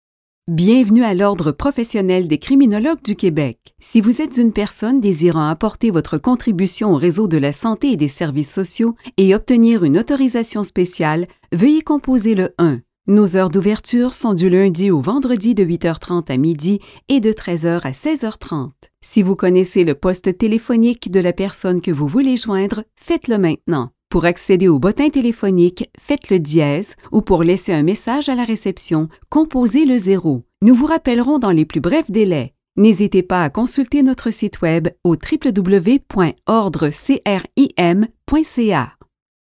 Message téléphonique